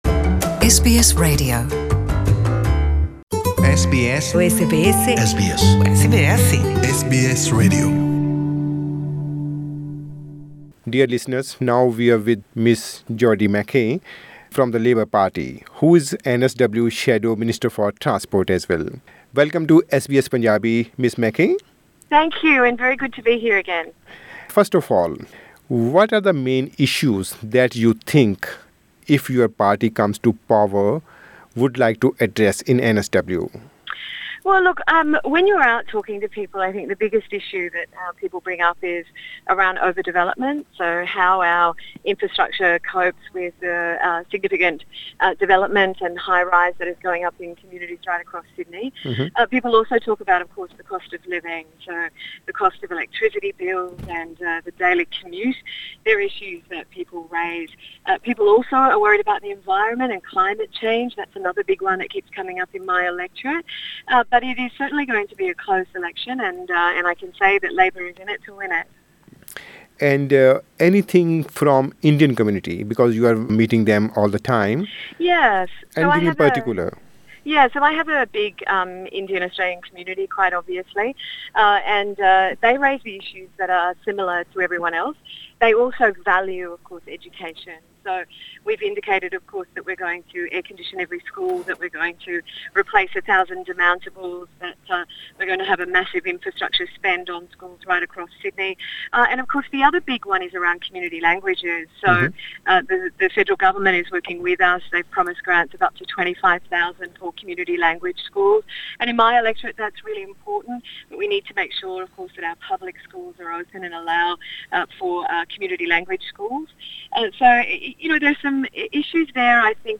Jodi McKay from Labor shares her party's agenda.